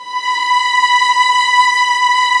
Index of /90_sSampleCDs/Roland LCDP13 String Sections/STR_Violins III/STR_Vls6 mf%f St